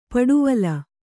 ♪ paḍuvala